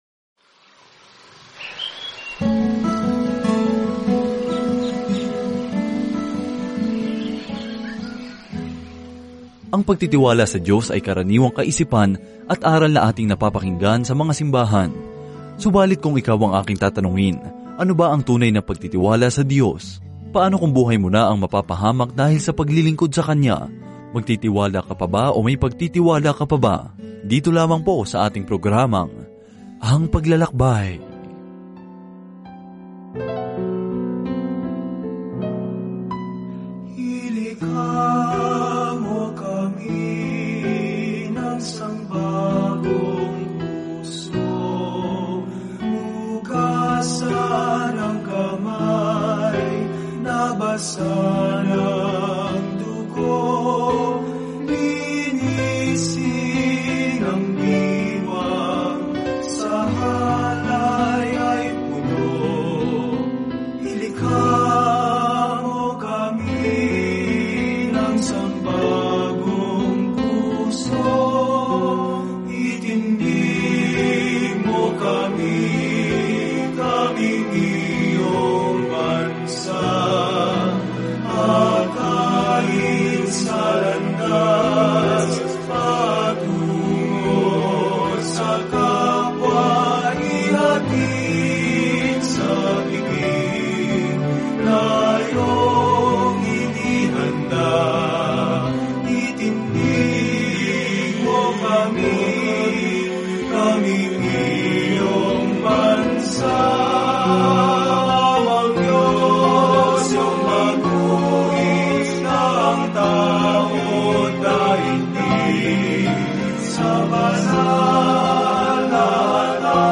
Banal na Kasulatan 1 Mga Hari 18:17-46 Araw 10 Umpisahan ang Gabay na Ito Araw 12 Tungkol sa Gabay na ito Ang aklat ng Mga Hari ay nagpatuloy sa kuwento kung paano umunlad ang kaharian ng Israel sa ilalim nina David at Solomon, ngunit kalaunan ay nagkalat. Araw-araw na paglalakbay sa 1 Mga Hari habang nakikinig ka sa audio study at nagbabasa ng mga piling talata mula sa salita ng Diyos.